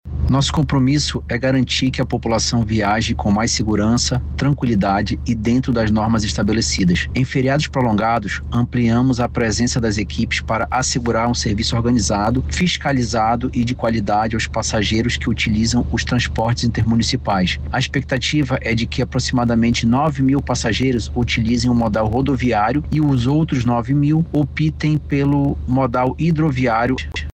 O diretor-presidente da Arsepam, Ricardo Lasmar, afirma que o reforço na operação garante mais segurança aos passageiros neste período de maior movimentação.
SONORA-ARSEPAM-.mp3